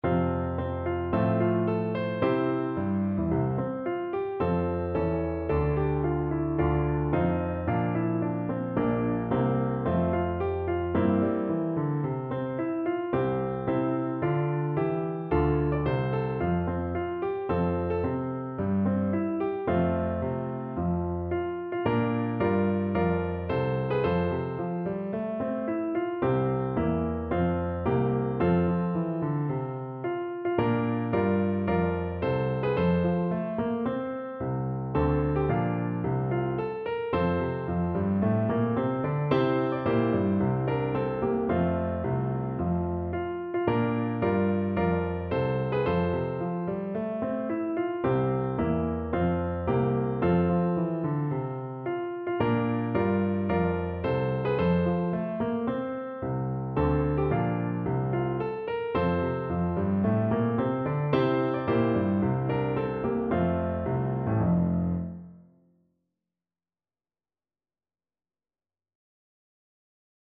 Allegro =c.110 (View more music marked Allegro)
4/4 (View more 4/4 Music)
National Anthems